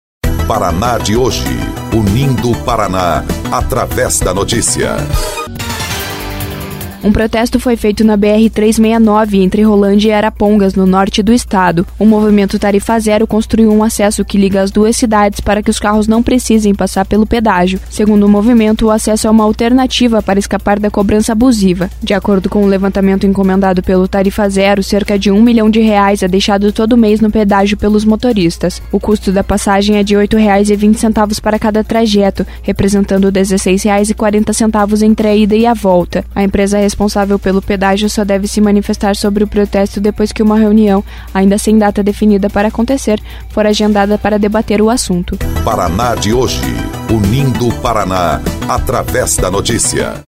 29.05 – BOLETIM – Protesto contra pedágio é feito na BR-369, entre Rolândia e Arapongas